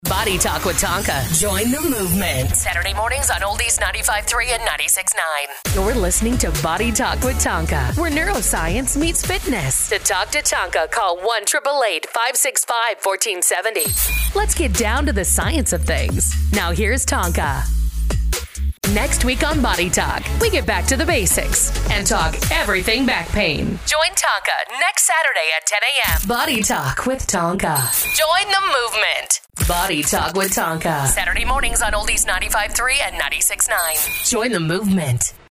American English Speaking Female Voiceover Artist & Broadcaster
American, Minnesotan/Midwestern
Middle Aged
BSVO_Podcast Demo 1.mp3